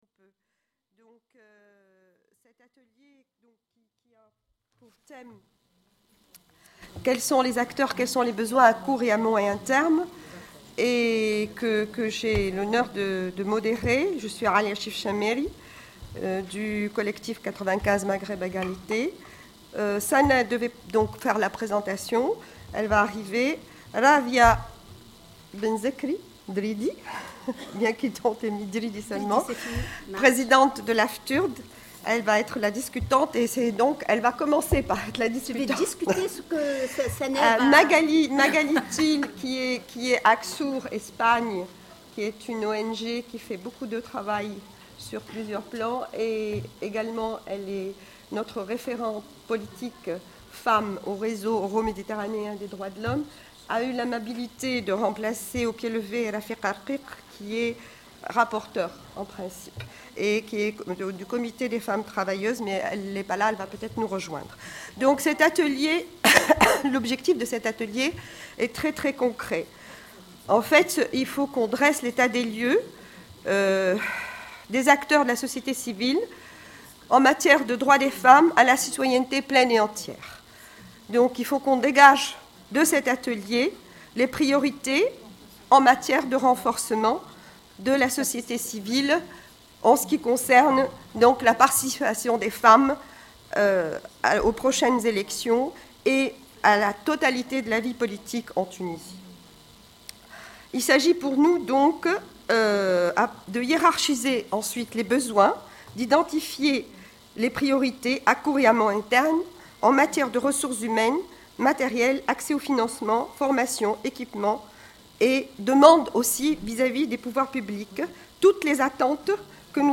Les 17, 18 et 19 mars derniers s'est tenu à Tunis un séminaire sur la transition démocratique en Tunisie. Pendant trois jours, des représentants de la société civile tunisienne, des experts internationaux et des politiques ont échangé sur la démocratie et sa construction. Tous les débats ont été enregistrés.
Discours d’ouverture par Souhayr Belhassen, Présidente de la FIDH. Allocution de Beji Caid Essebsi, Premier ministre du gouvernement tunisien.